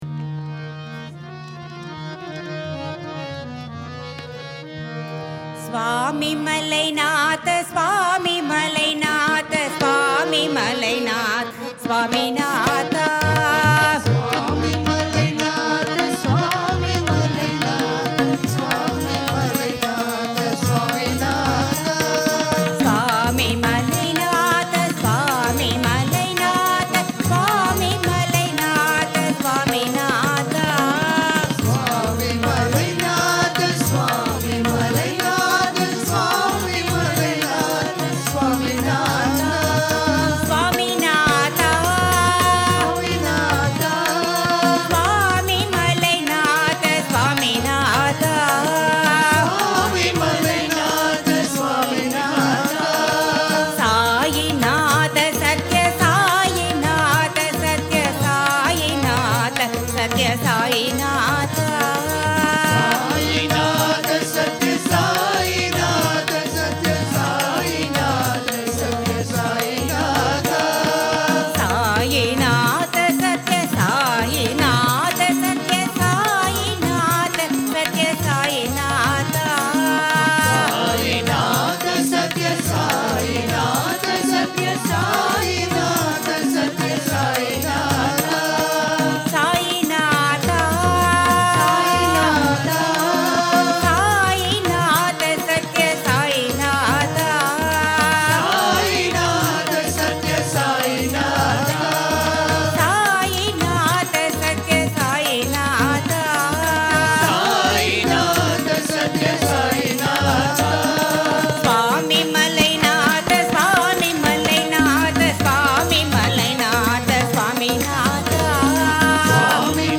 1. Devotional Songs
Jaunpuri
8 Beat / Keherwa / Adi
Medium Fast
5 Madhyam / C
2 Madhyam / G